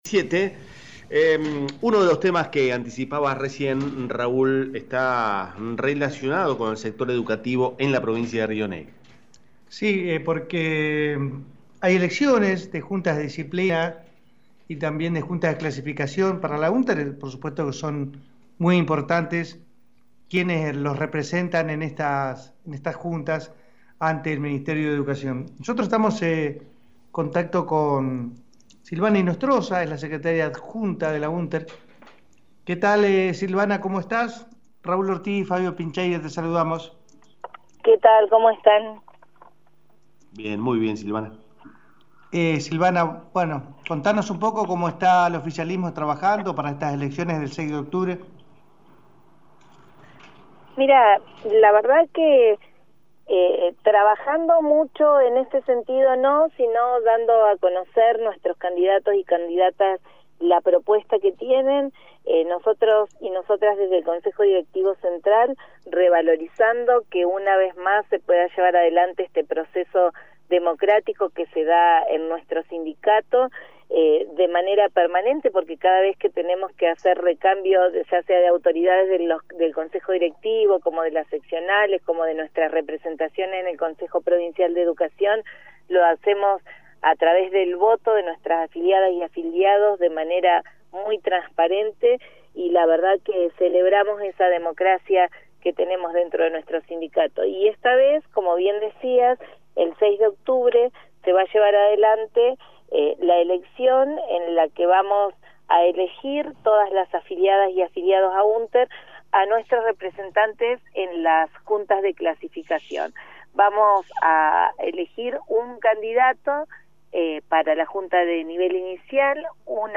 Entrevista de radio